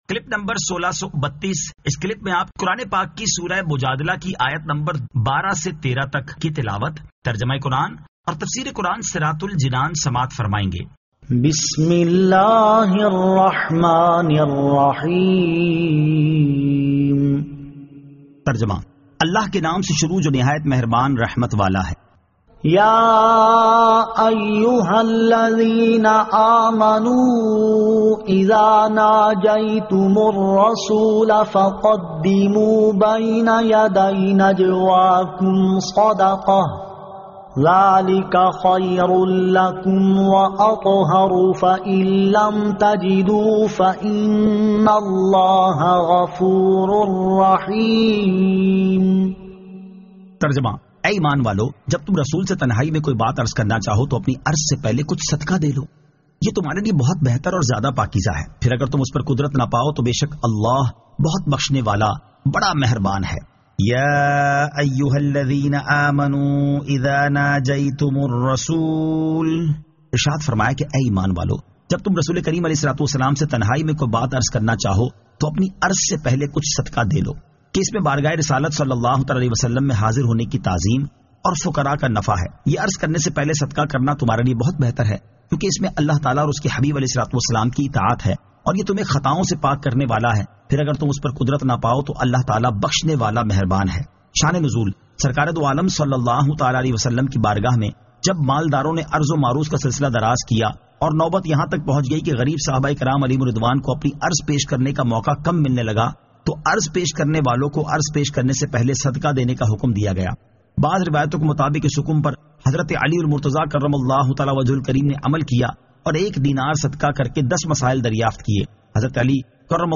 Surah Al-Mujadila 12 To 13 Tilawat , Tarjama , Tafseer